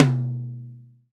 TOM TOM 91.wav